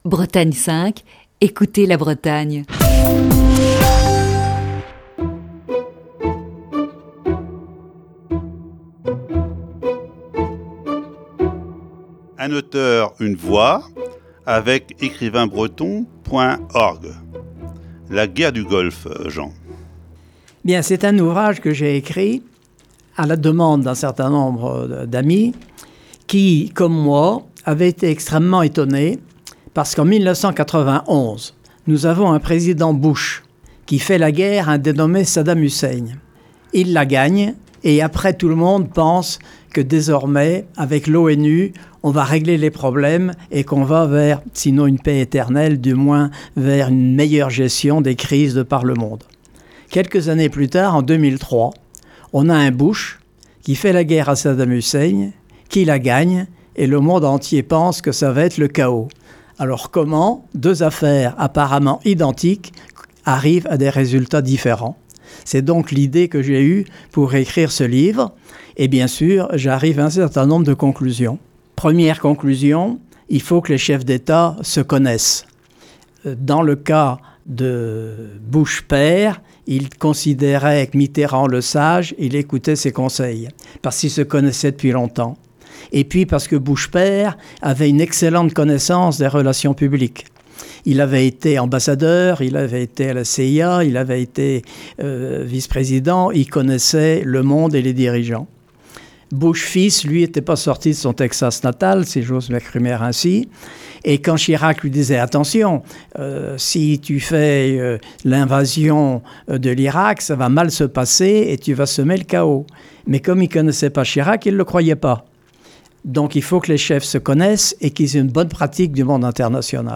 Ce jeudi, quatrième partie de cet entretien.